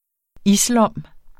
Udtale [ ˈis- ]